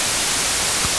clap-detection
clap-07_noise0.1.wav